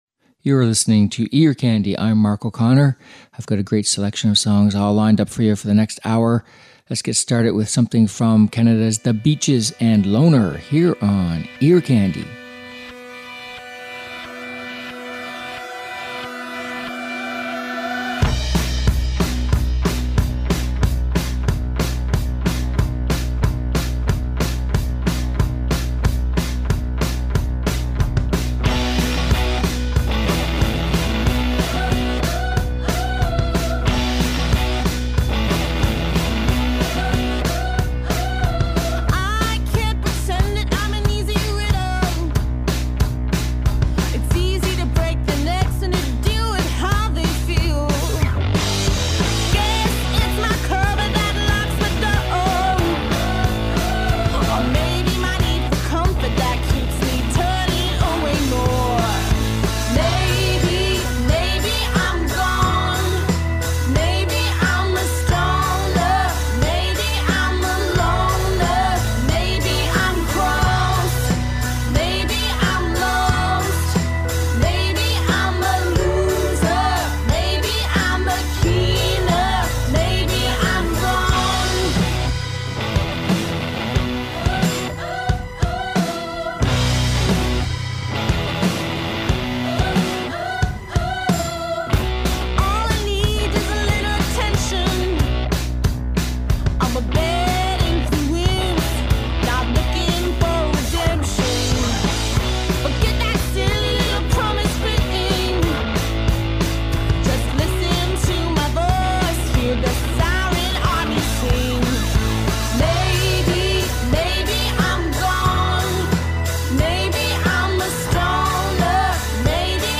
Uptempo Rock and Pop Songs